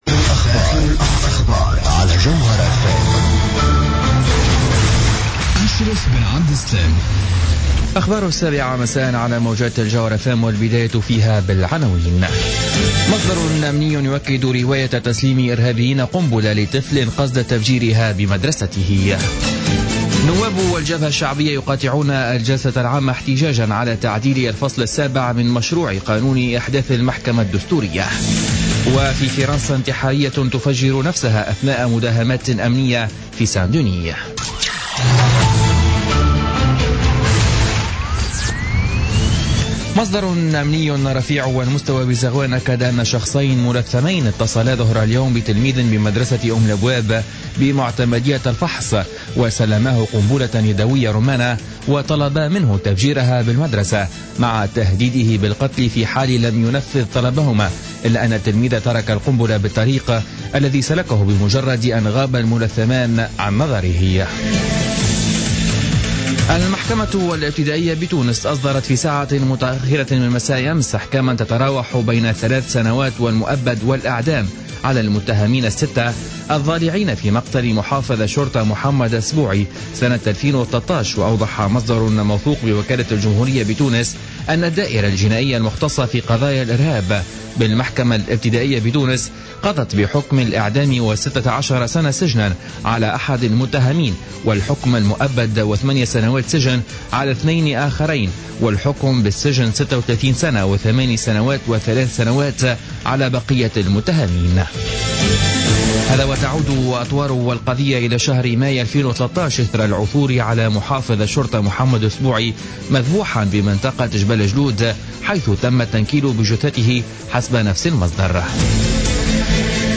نشرة أخبار الساعة السابعة مساء ليوم الأربعاء 18 نوفمبر 2015